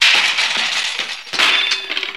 Metal Parts Clanging and Crashing